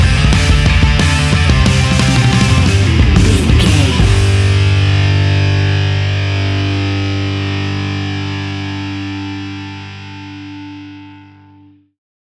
Epic / Action
Fast paced
Ionian/Major
hard rock
distortion
punk metal
rock guitars
Rock Bass
Rock Drums
heavy drums
distorted guitars
hammond organ